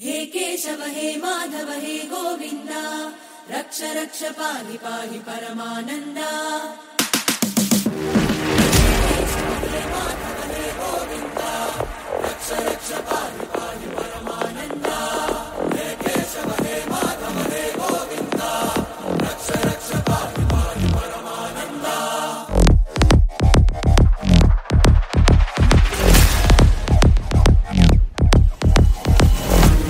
Ringtones Category: Bollywood ringtones